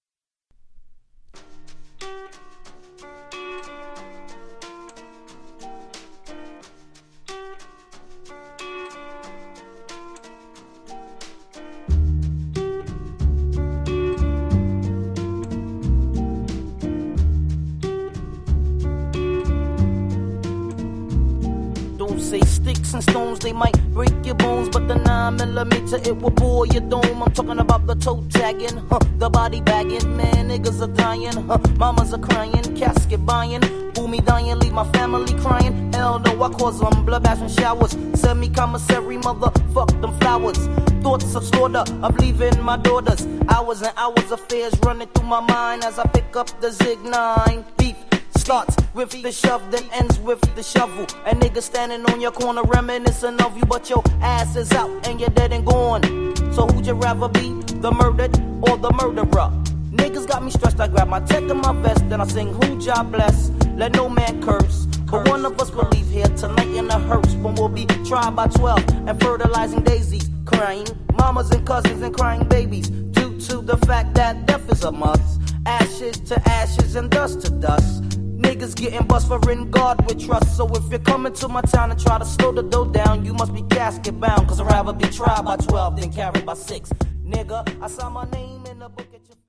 92 bpm